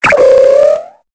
Cri de Karaclée dans Pokémon Épée et Bouclier.